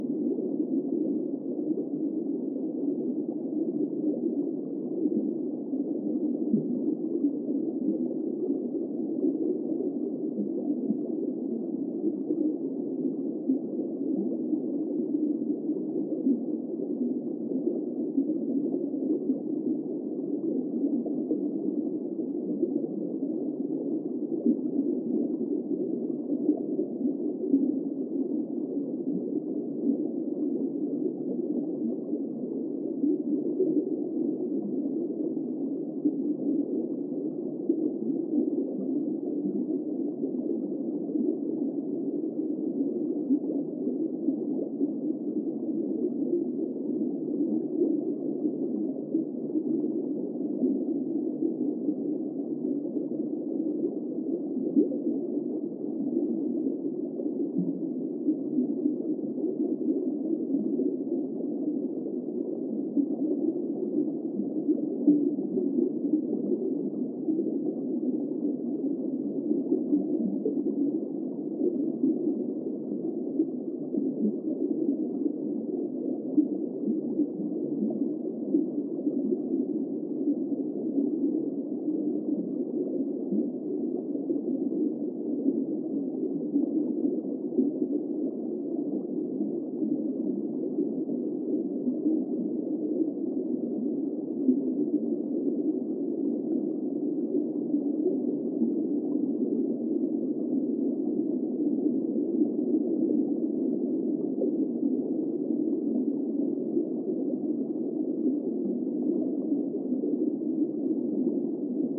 Звуки подводного мира
Вариант 2 с чуть меньшей глубиной